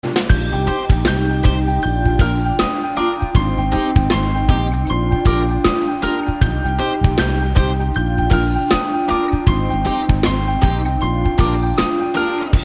• Качество: 192, Stereo
спокойные
без слов
инструментальные
пианино